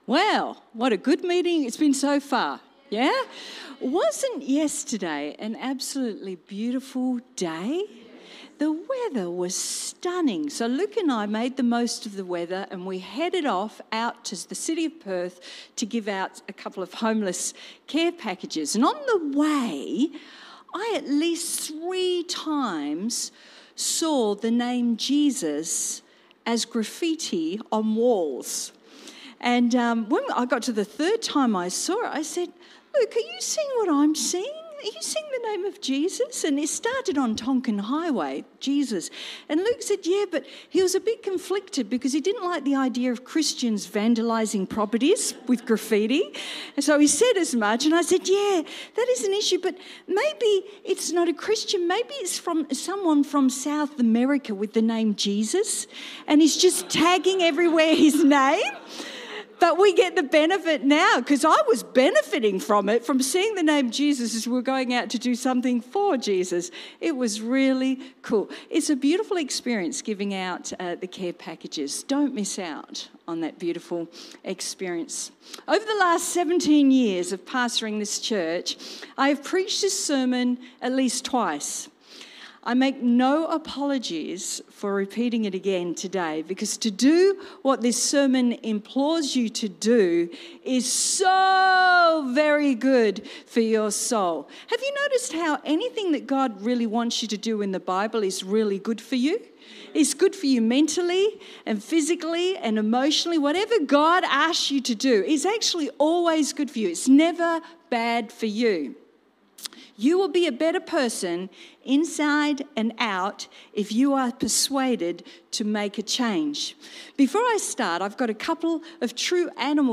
Cityview-Church-Sunday-Service-Honking-Encouragement.mp3